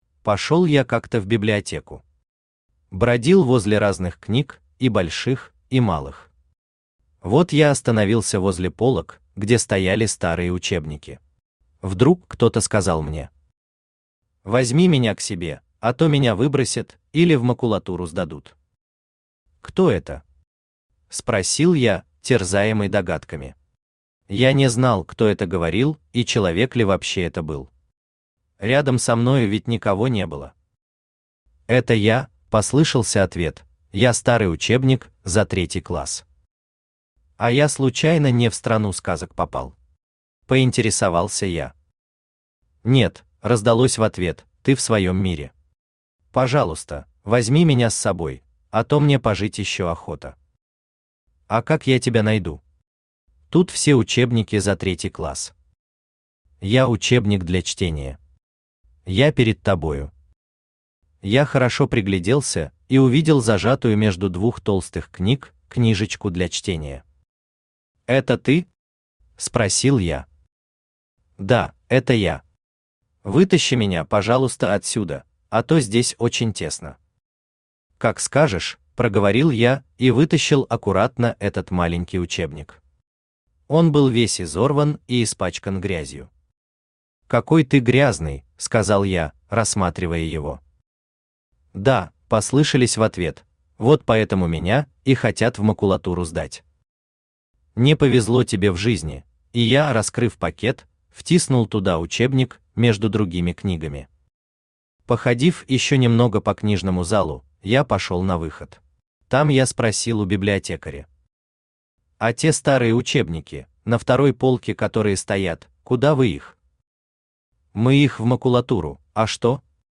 Аудиокнига История старого учебника | Библиотека аудиокниг
Aудиокнига История старого учебника Автор Василий Валерьевич Курочкин Читает аудиокнигу Авточтец ЛитРес.